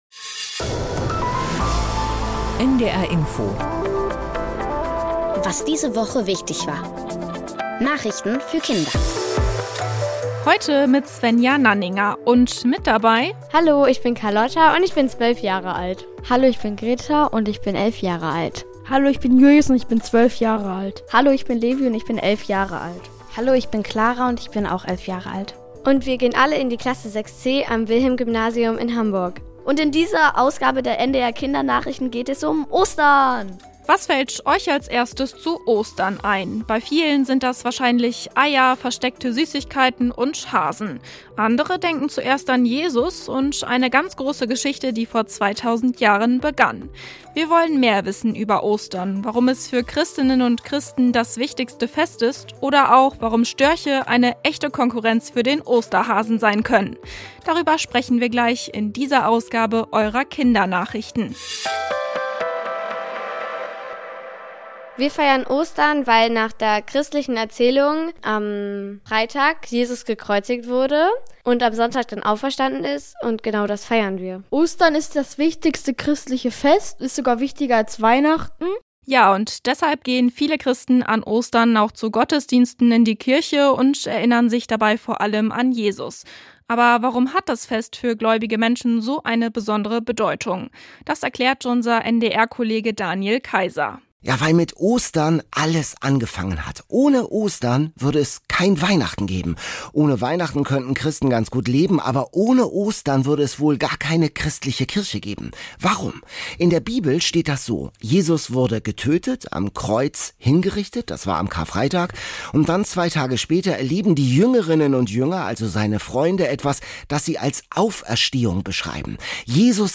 Was diese Woche in Deutschland und der Welt wichtig war erfährst du jeden Samstag hier in den Nachrichten für Kinder von NDR Info. Wir sprechen mit Kindern über die Nachrichtenthemen der Woche und erklären sie verständlich in einfacher Sprache.